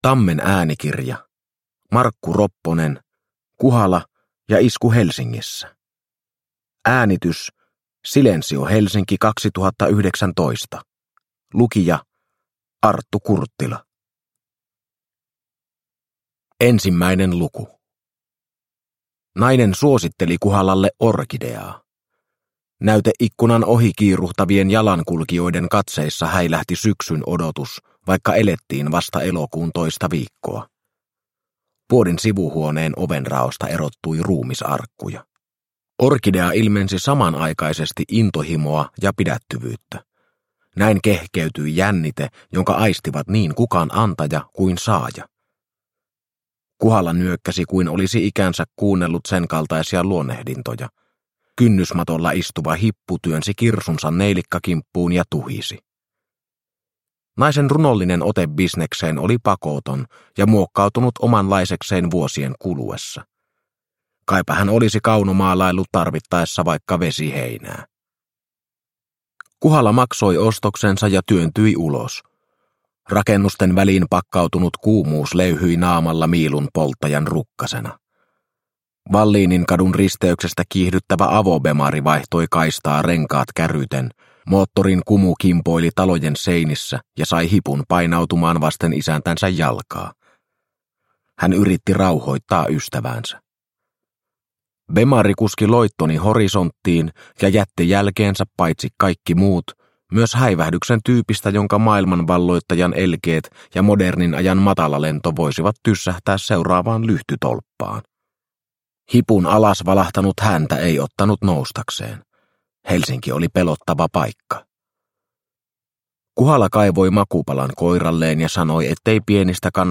Kuhala ja isku Helsingissä – Ljudbok – Laddas ner